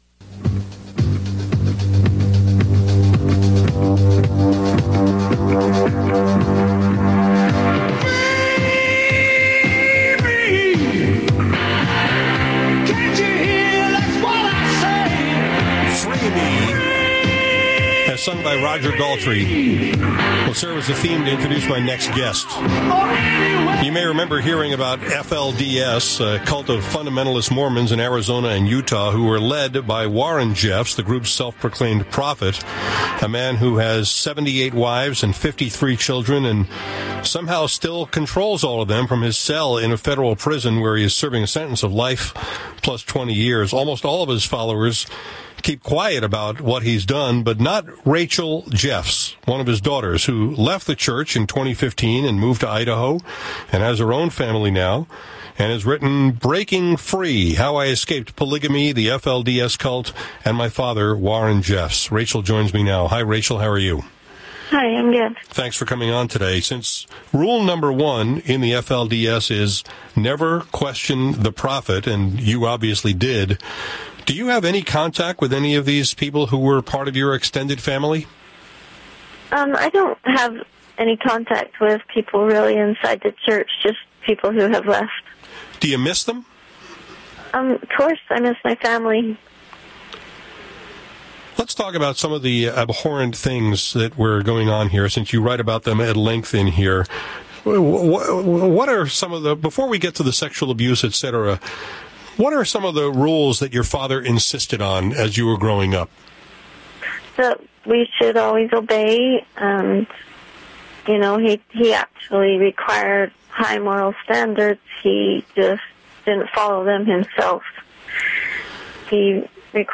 This was a tough interview